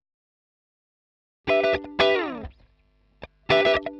120_Guitar_funky_riff_E_5.wav